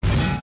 iron_jump.ogg